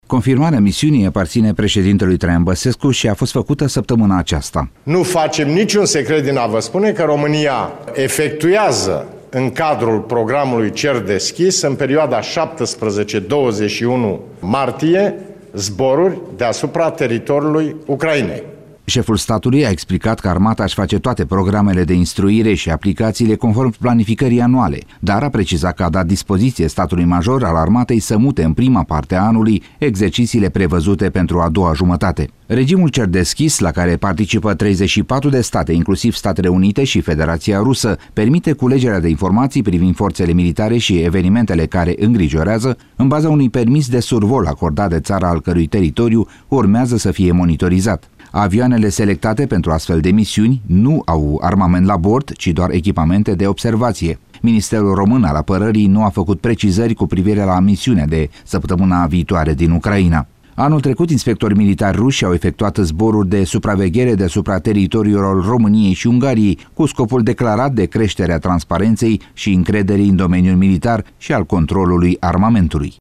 Cu detalii corespondentul Radio Romania